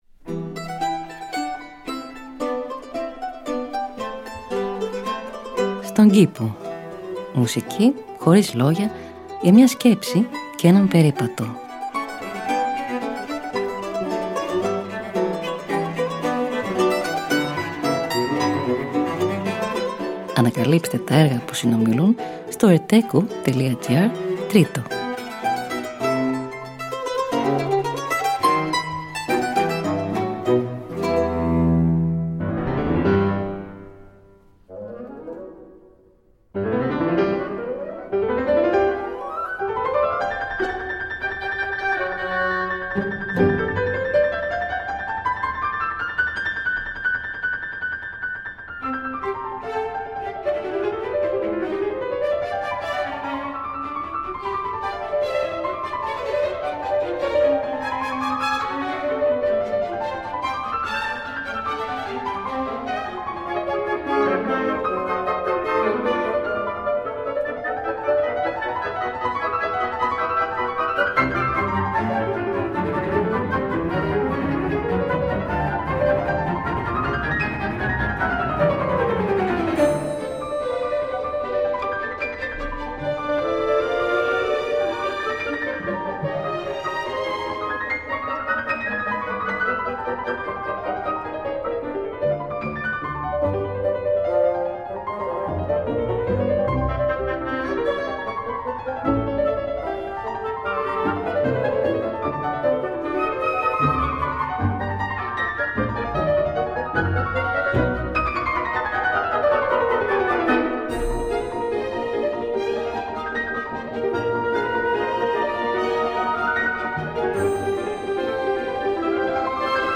Μουσική Χωρίς Λόγια για μια Σκέψη και έναν Περίπατο.
Arrange for mandolin and continuo